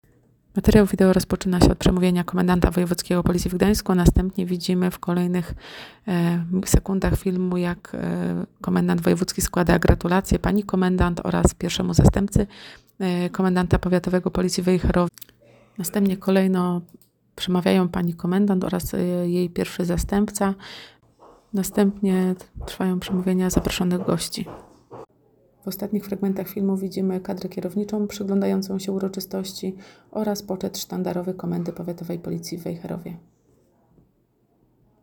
Uroczystość powołania Komendanta Powiatowego Policji w Wejherowie